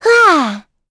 Gremory-Vox_Attack5.wav